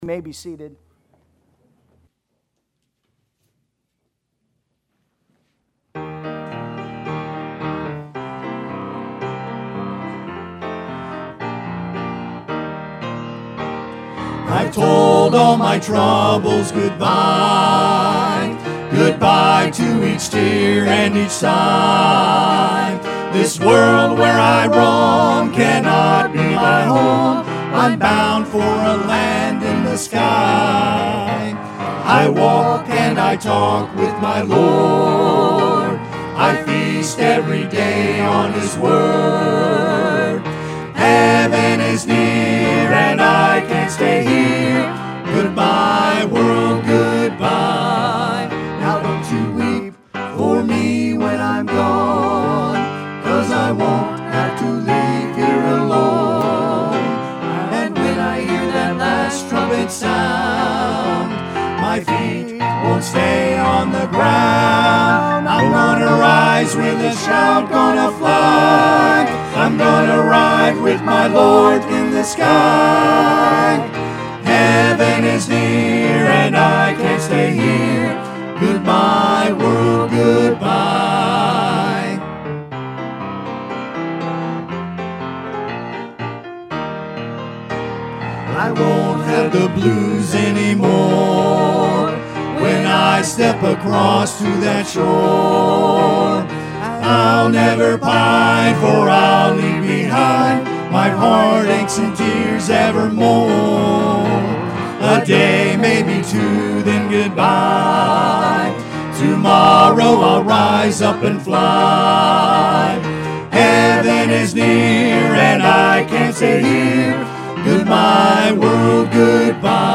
Sunday AM